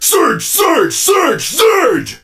surge_lead_vo_03.ogg